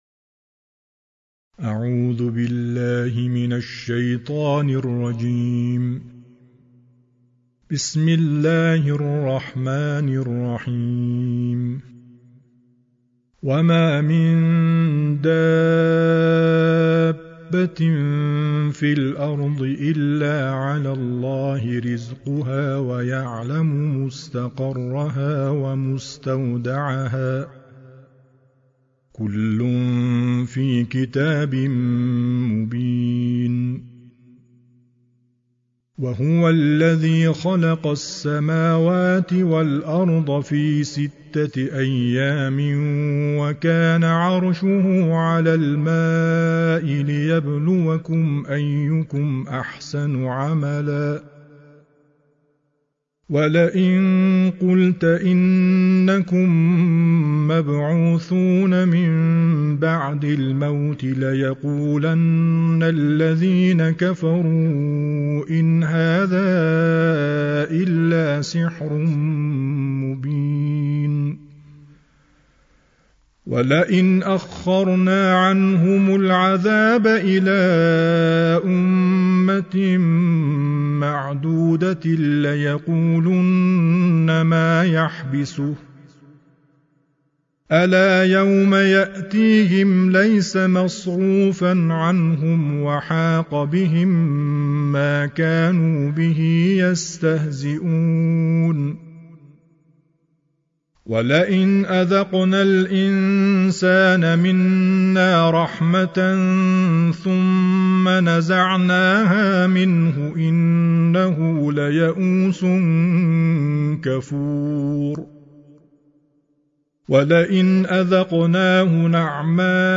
ترتيل القرآن الكريم